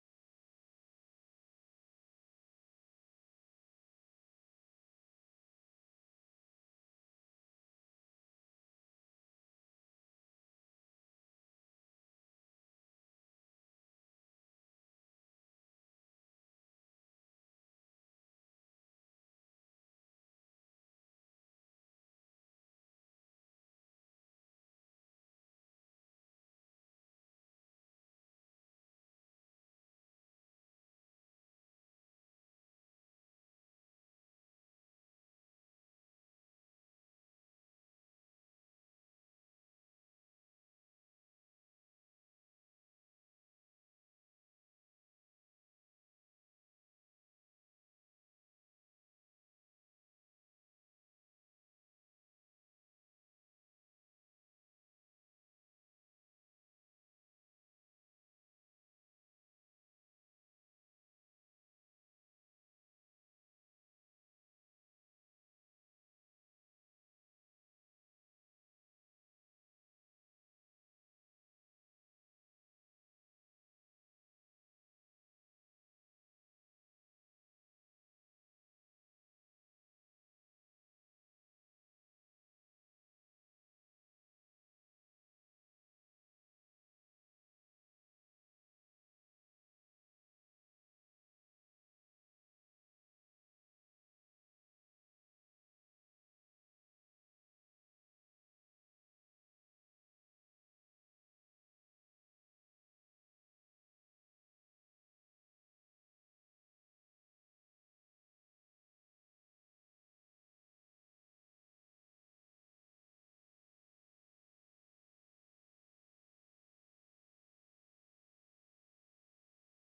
Město Varnsdorf: 7. zasedání Zastupitelstva města Varnsdorf Místo konání: Studentské centrum Střelnice Doba konání: 20. června 2019 od 15:00 hod. 1.